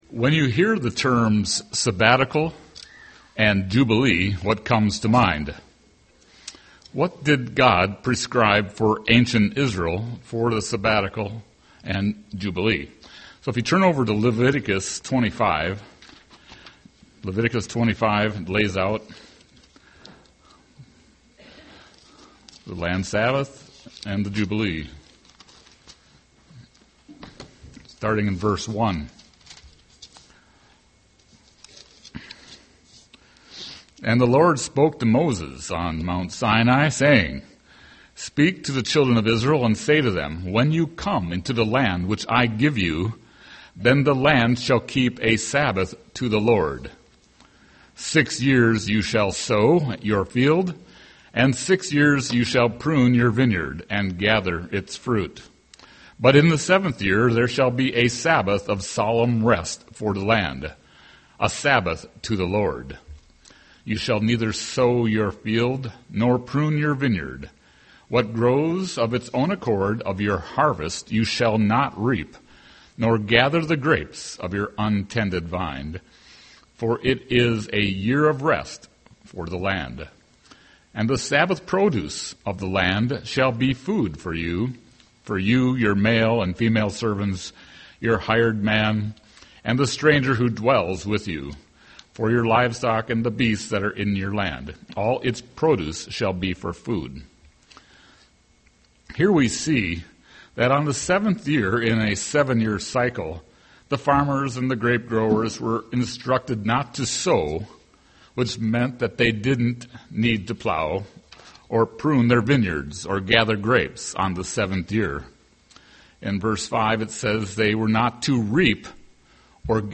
Given in Twin Cities, MN
UCG Sermon jubilee Studying the bible?